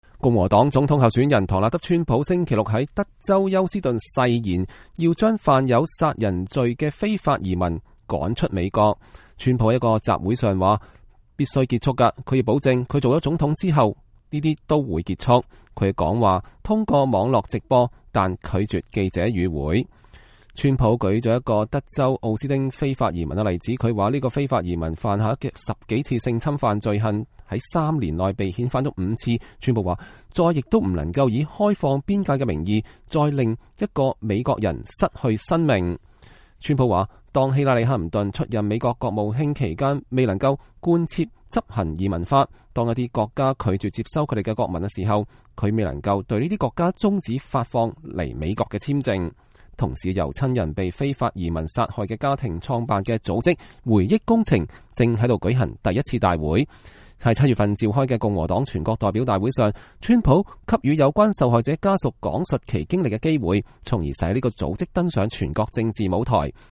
唐納德川普在德州休斯頓一個集會上